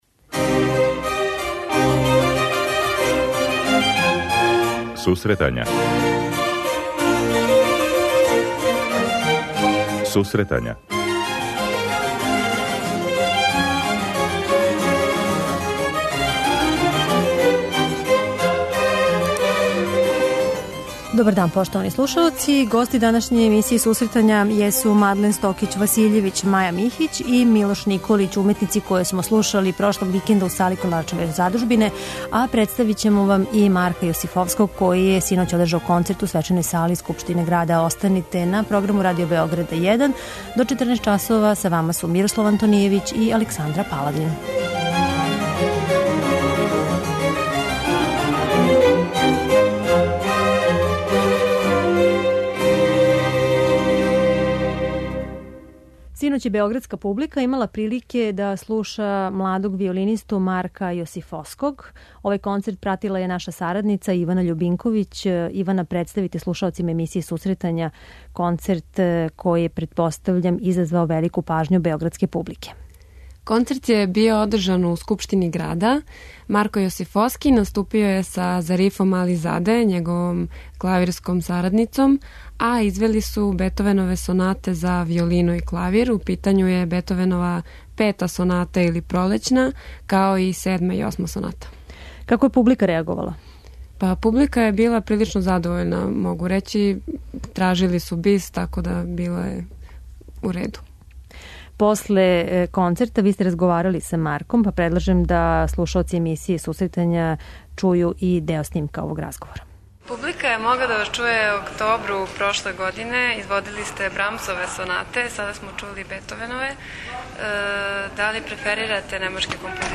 преузми : 23.72 MB Сусретања Autor: Музичка редакција Емисија за оне који воле уметничку музику.